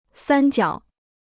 三角 (sān jiǎo): triangle.